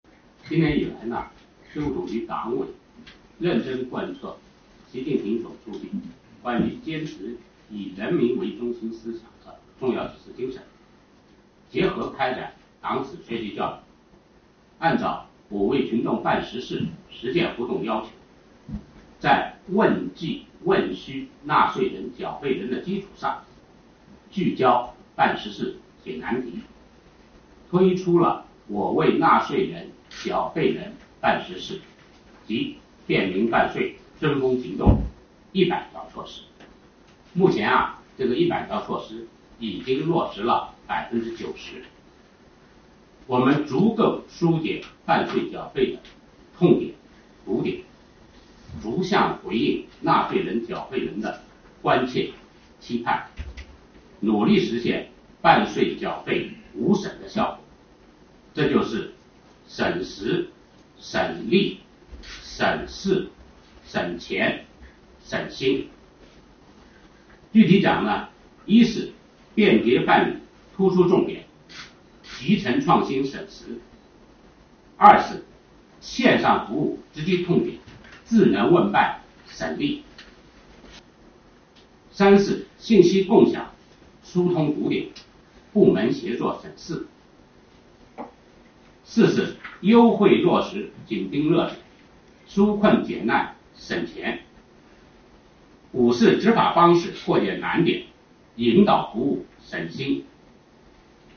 国家税务总局日前召开新闻发布会，就便民办税缴费情况、新办涉税市场主体发展情况和税收工作服务北京冬奥会等内容回答记者提问。